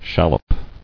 [shal·lop]